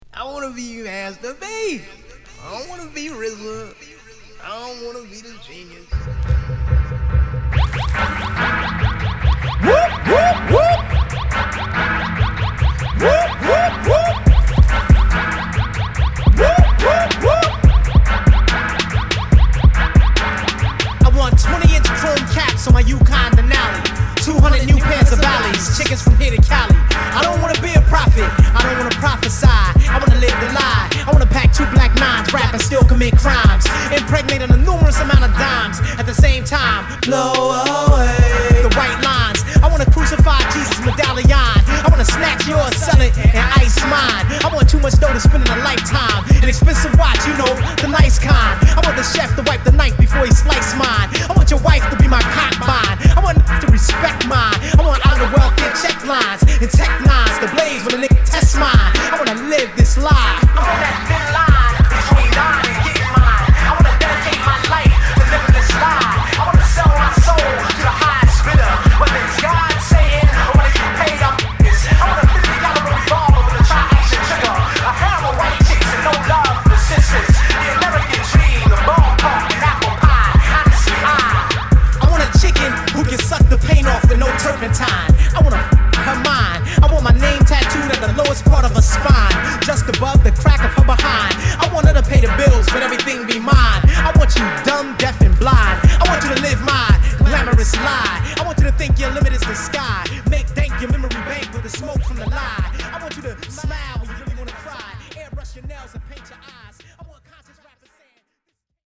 変則ビートに倍速で器用に歌いこなす